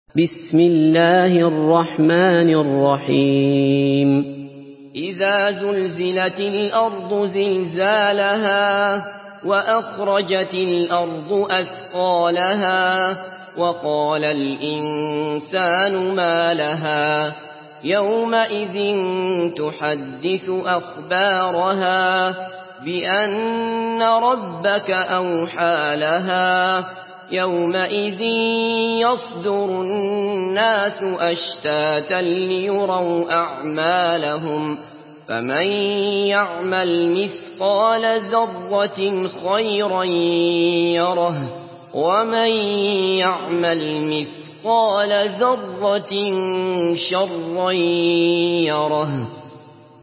Surah Az-Zalzalah MP3 by Abdullah Basfar in Hafs An Asim narration.
Murattal